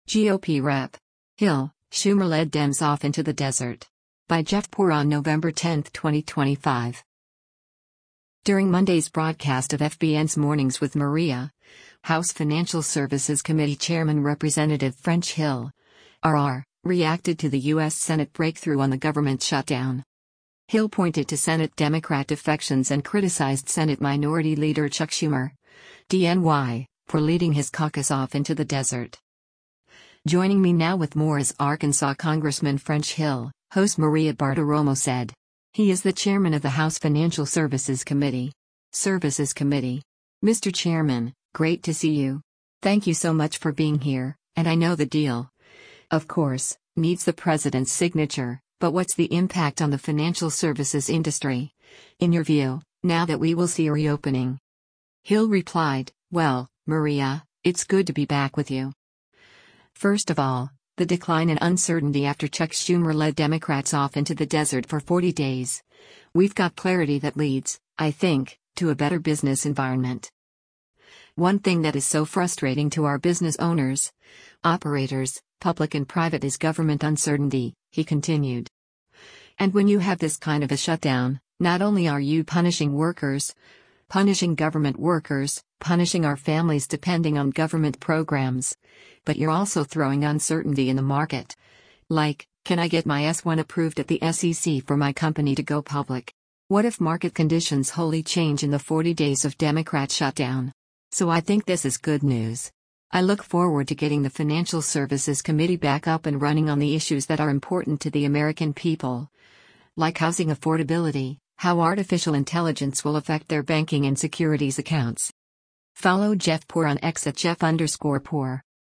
During Monday’s broadcast of FBN’s “Mornings with Maria,” House Financial Services Committee chairman Rep. French Hill (R-AR) reacted to the U.S. Senate breakthrough on the government shutdown.